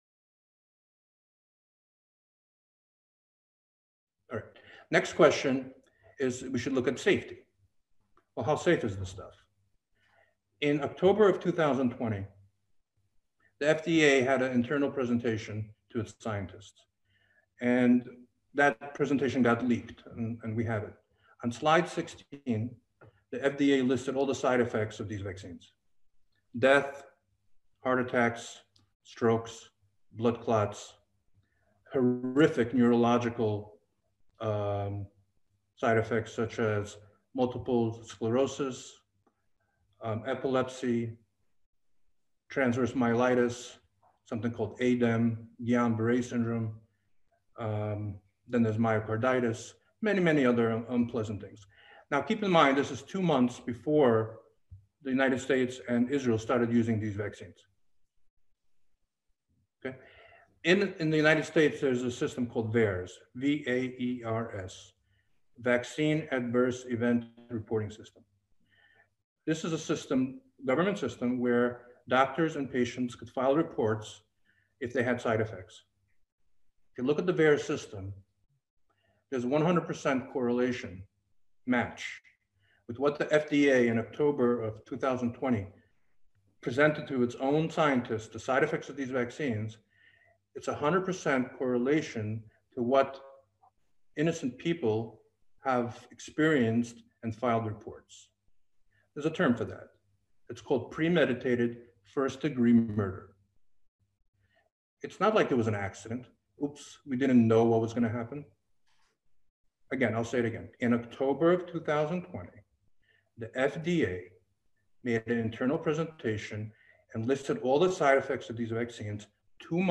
בית הדין ברוקלין: ד"ר ולדימיר זלנקו (חלק ב') רופא משפחה בכיר מפתח "פרוטוקול זלנקו קוביד-19" מעיד בפני בית הדין על נזקי החיסונים בהשוואה לנזקי קוביד-19 בהשוואה לחיסונים בשנים קודמות
ליקטנו עבורכם קטעים חשובים מתוך העדויות שנמסרו לבית הדין ע"י המומחים באסיפה שנערכה בתאריך 21.11.2021.